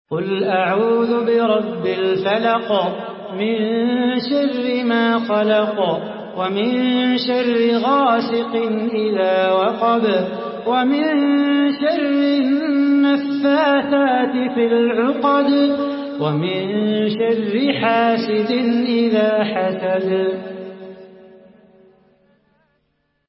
Surah Al-Falaq MP3 in the Voice of Salah Bukhatir in Hafs Narration
Murattal Hafs An Asim